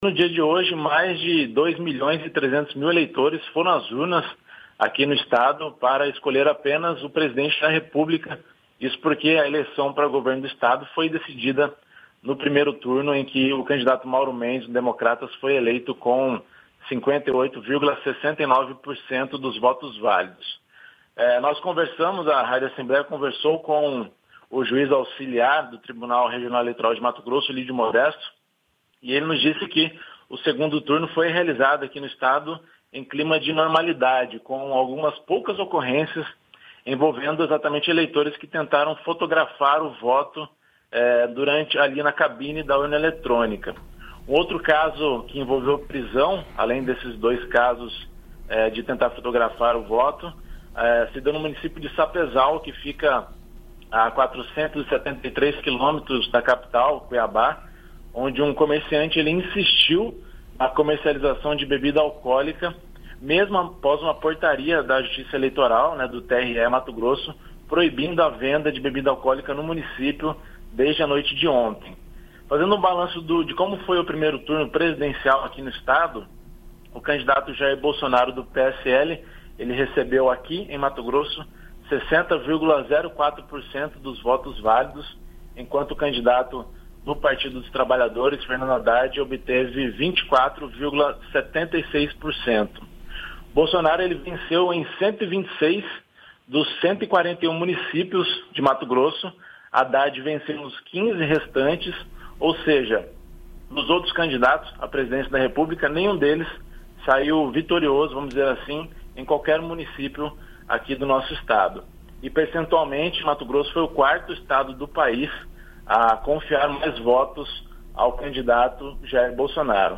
RadioAgência Senado